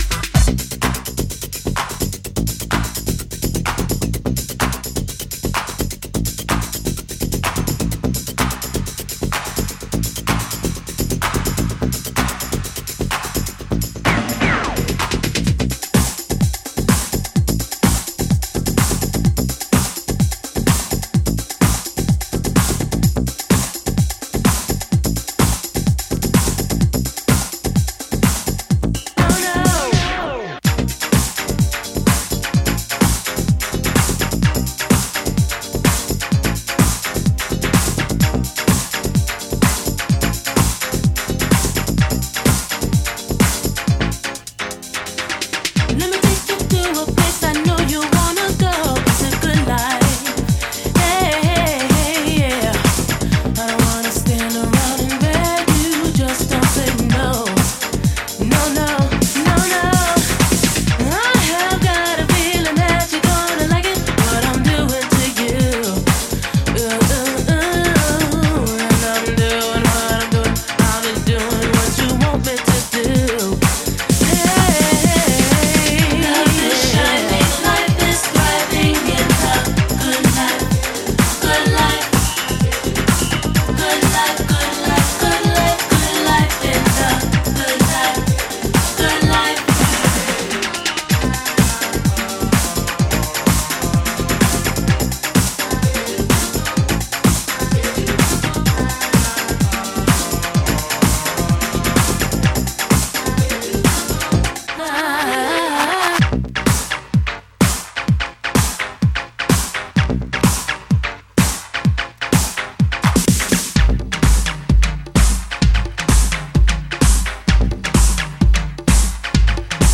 club/house